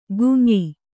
♪ gum`yi